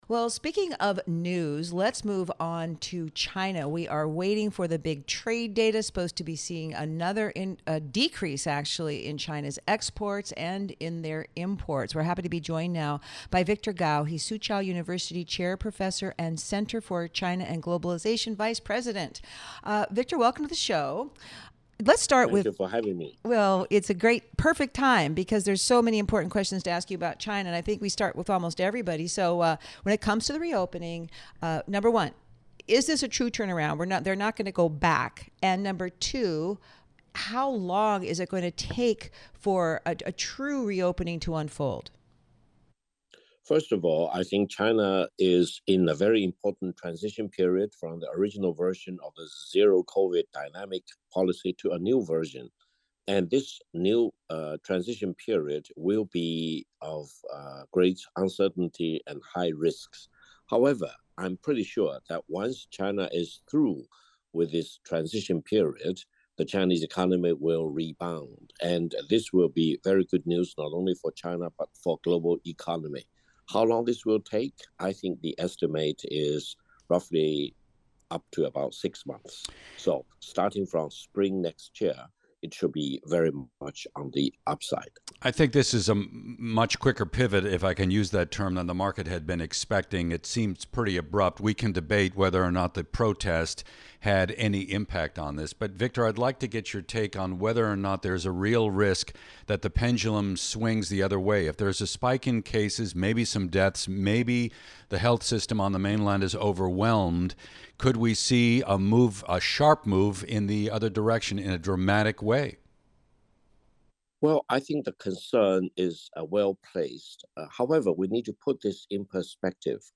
Victor Gao on China (Radio)